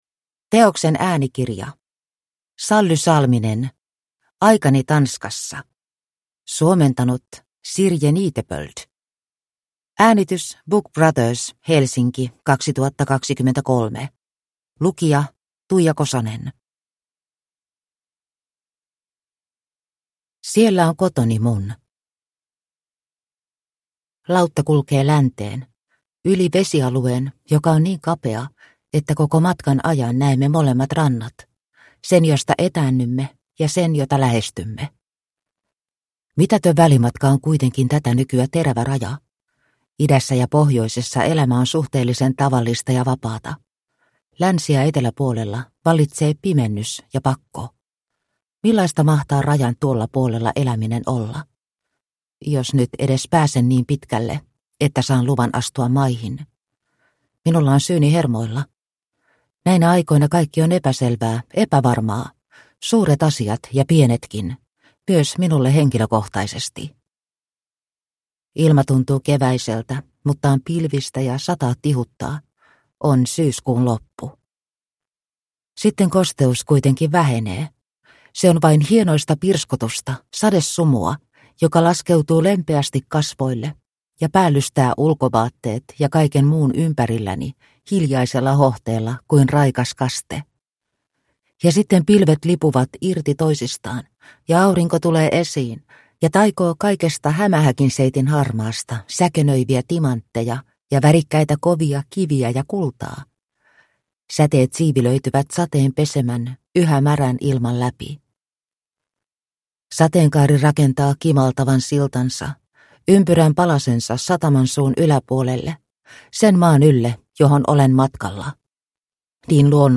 Aikani Tanskassa – Ljudbok – Laddas ner